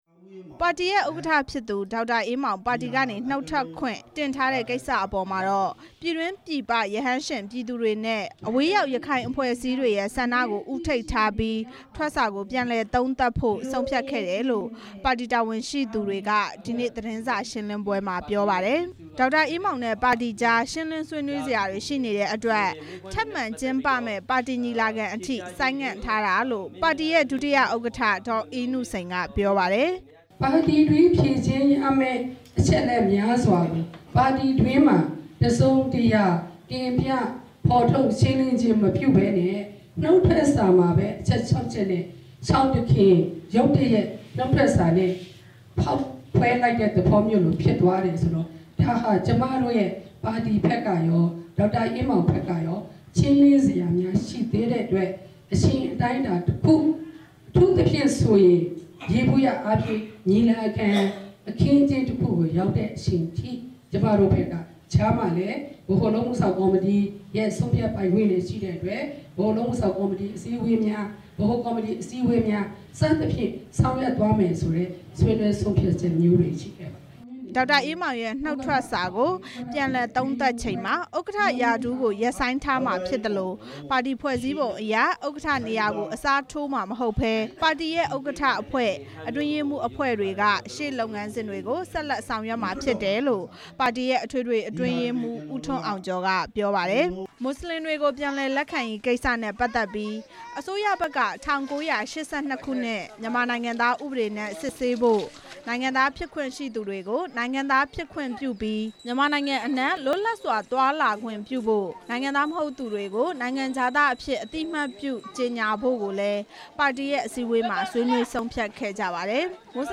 ရခိုင်အမျိုးသားပါတီ သတင်းစာရှင်းလင်းပွဲ